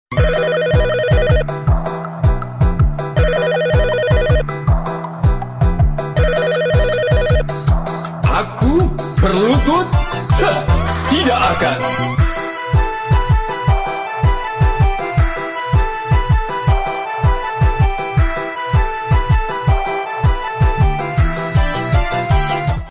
Genre: Nada dering remix